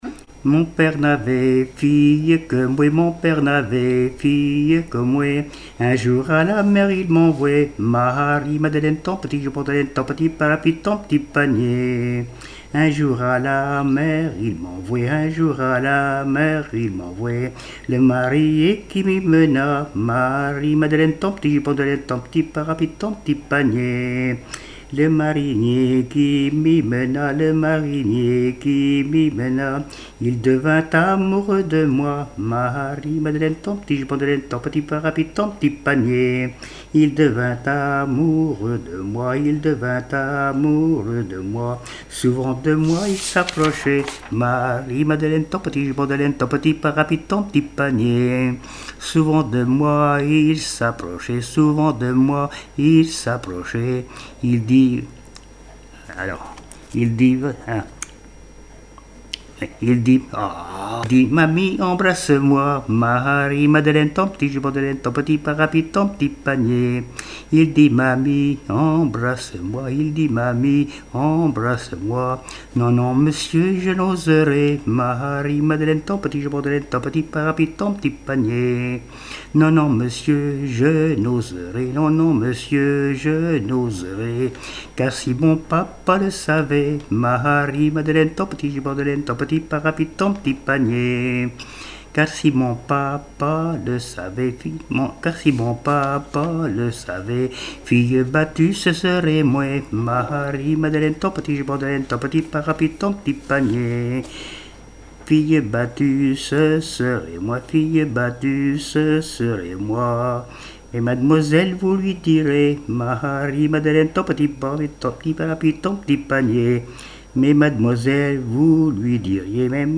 Mémoires et Patrimoines vivants - RaddO est une base de données d'archives iconographiques et sonores.
chansons et témoignages
Pièce musicale inédite